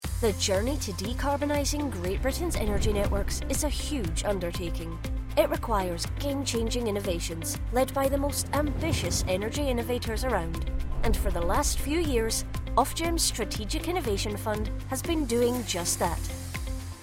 Engels (Schotland)
Jong, Speels, Veelzijdig, Vriendelijk, Warm
Corporate